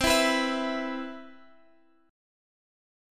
E5/C chord